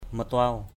/mə-tʊaʊ/